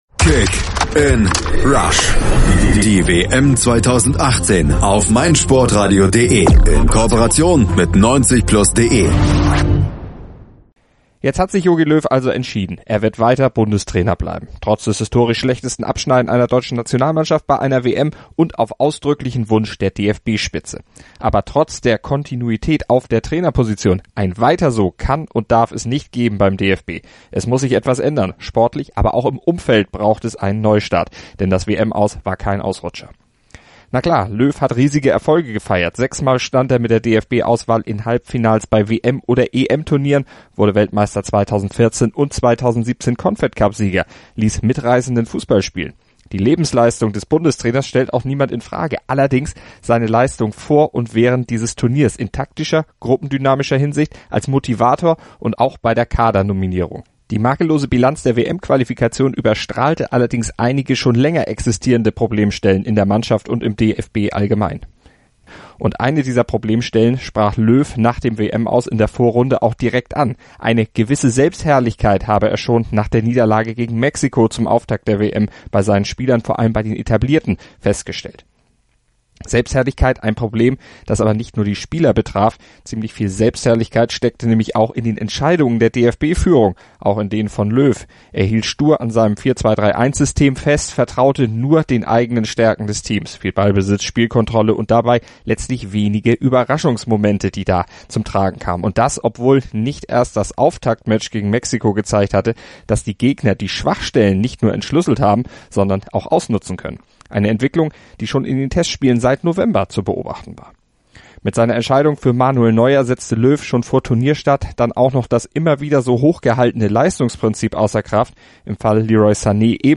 Kommentar: Bitte kein "Weiter so"